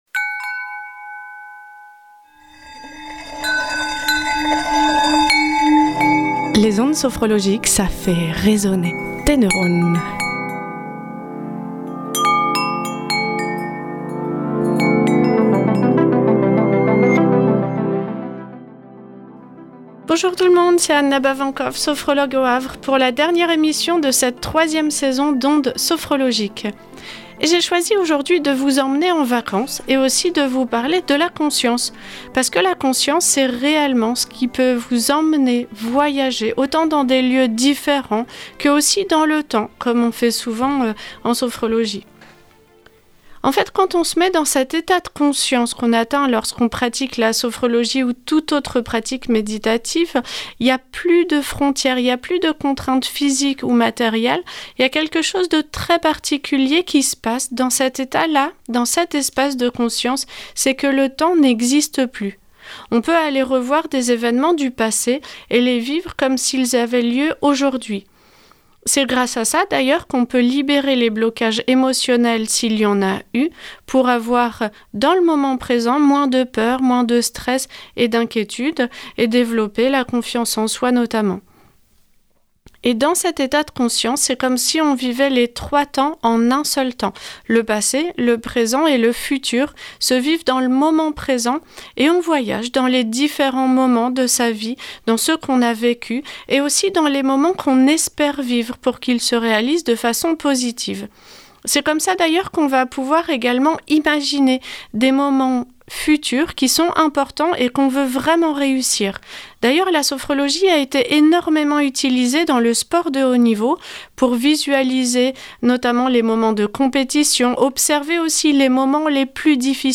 Podcast de la séance de sophrologie "La conscience"